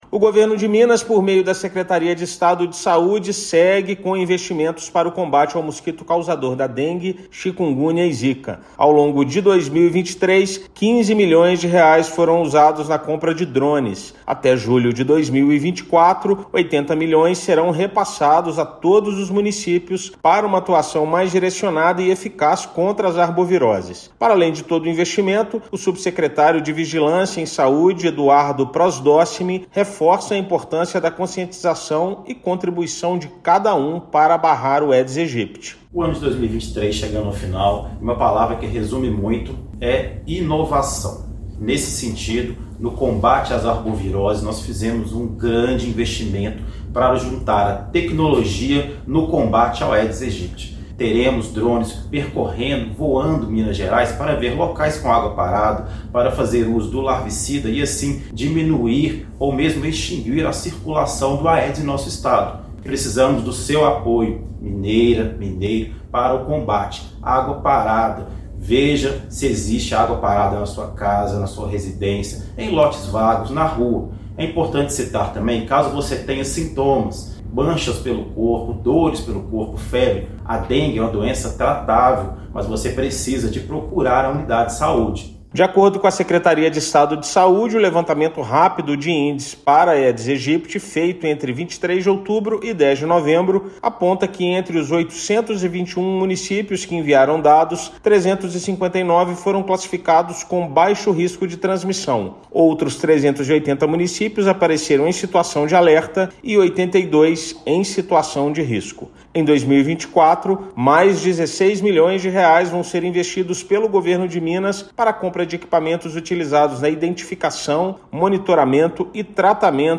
Governo do Estado investe em tecnologia para impedir proliferação do mosquito da dengue, zika e chikungunya com a chegada do período chuvoso. Ouça matéria de rádio.